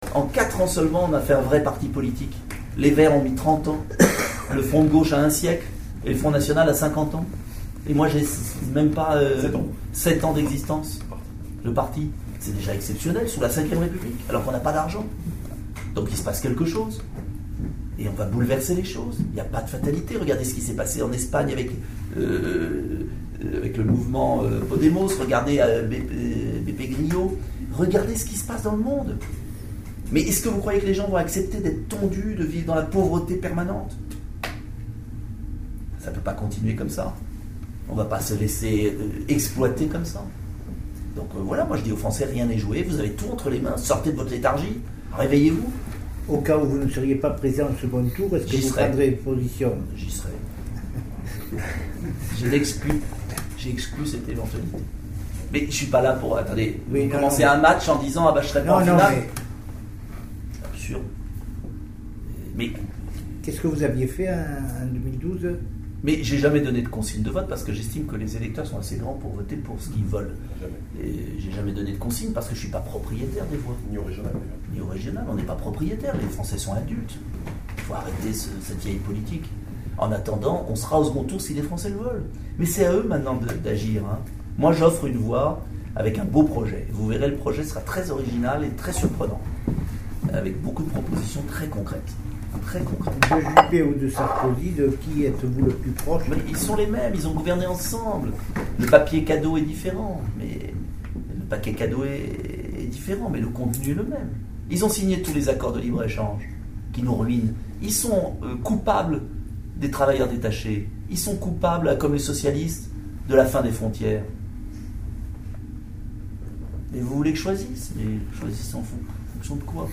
La conférence de presse
A son arrivée à l’aéroport de Tarbes-Lourdes-Pyrénées, Nicolas Dupont-Aignan a tenu une conférence de presse dans une salle de l’aérogare d’affaires.